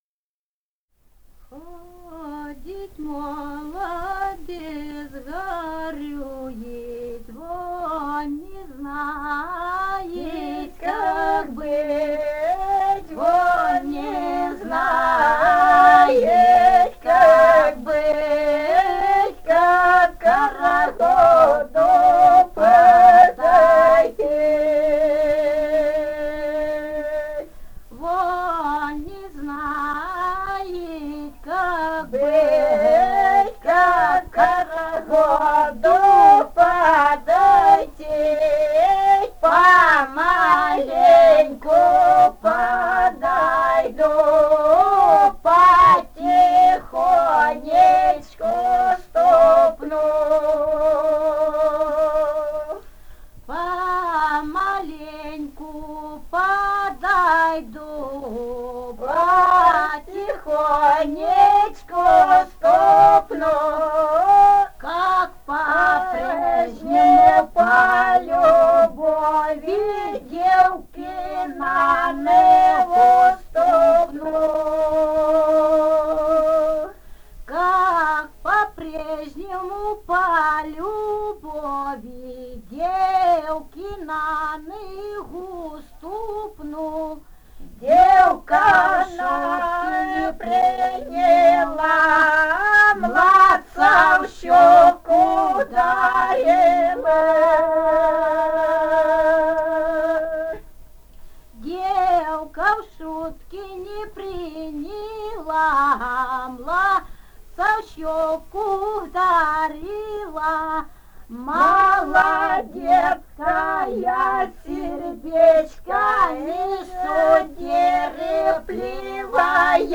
Этномузыкологические исследования и полевые материалы
«Ходит молодец, горюет» (хороводная на масленицу).
Румыния, с. Переправа, 1967 г. И0973-07